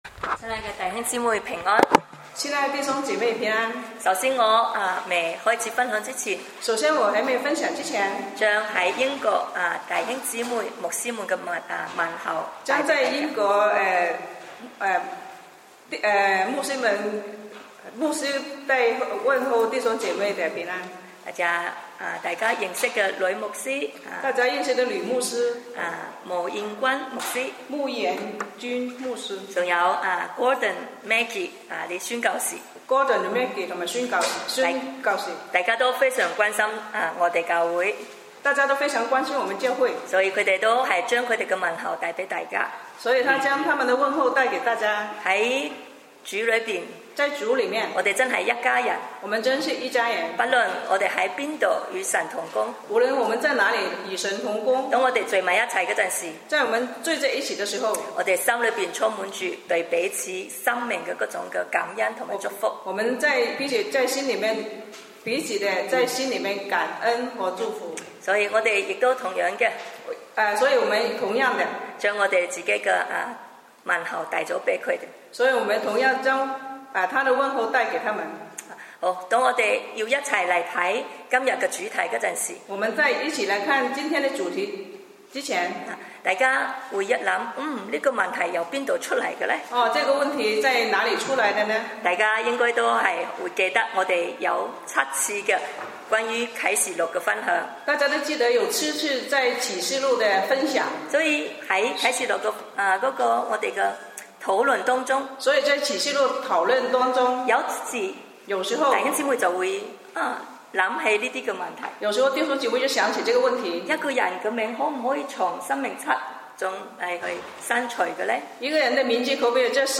主日讲道音频